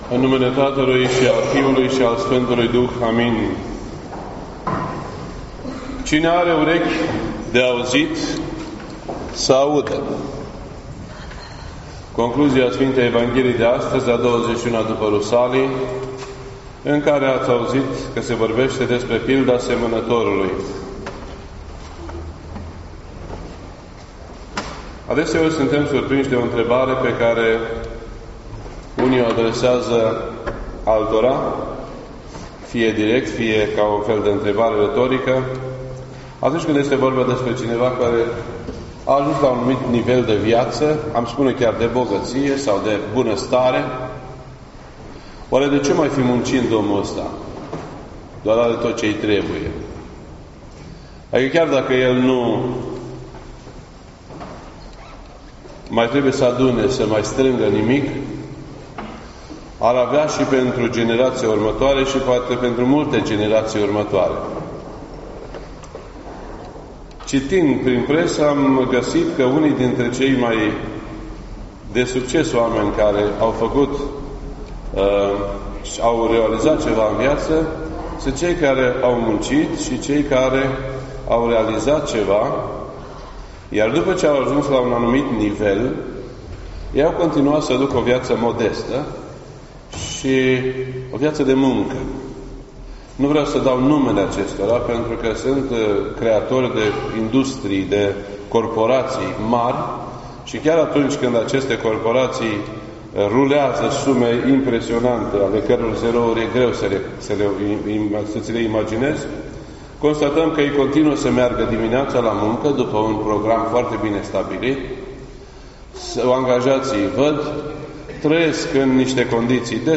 This entry was posted on Sunday, October 15th, 2017 at 1:57 PM and is filed under Predici ortodoxe in format audio.